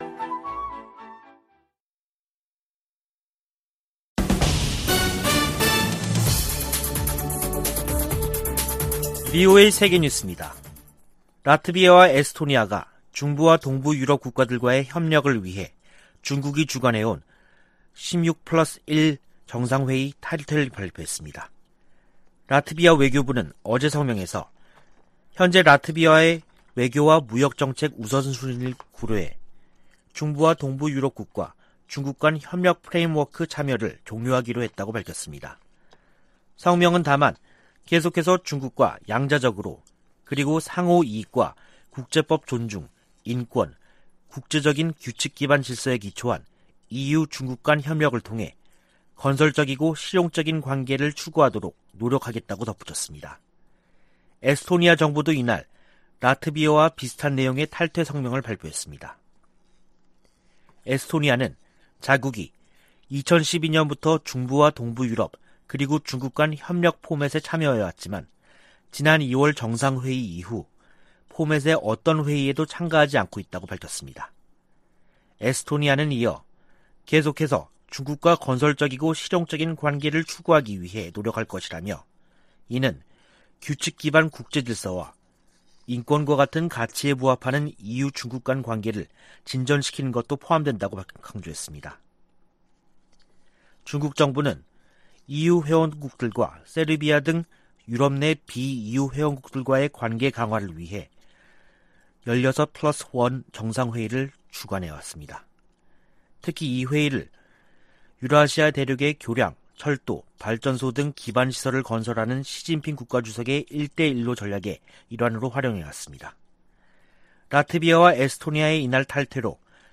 VOA 한국어 간판 뉴스 프로그램 '뉴스 투데이', 2022년 8월 12일 2부 방송입니다. 방한한 안토니우 구테흐스 유엔 사무총장은 북한의 완전하고 검증 가능하며 불가역적인 비핵화를 지지한다고 밝혔습니다. 윤석열 한국 대통령은 에드 마키 미 상원 동아태소위원장을 접견하고 동맹 강화와 한일 경제 협력에 애써 준 데 대해 감사의 뜻을 전했습니다. 미 국무부는 ‘코로나 방역전 승리’를 주장한 북한이 국제사회 백신 지원 제안을 수용해야 한다고 강조했습니다.